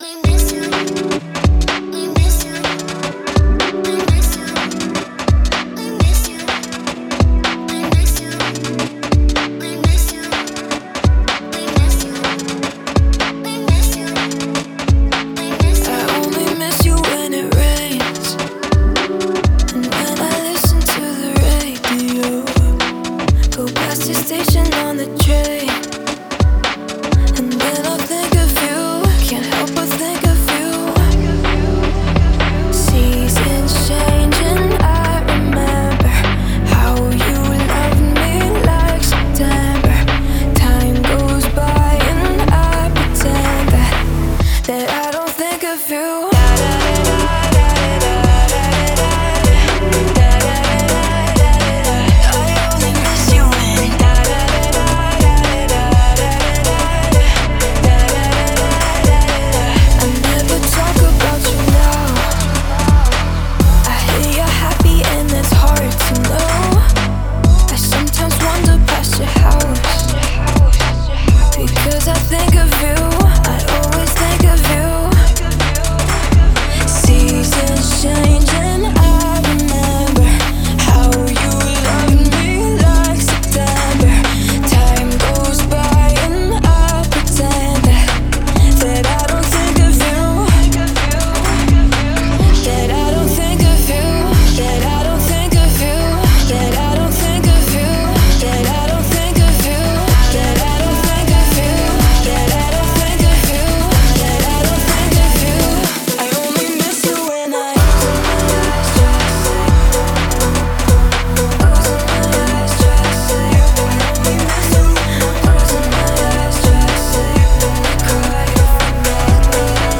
это энергичный трек в жанре EDM
мелодичного вокала